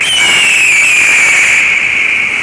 Eagle Sound Effect Free Download
Eagle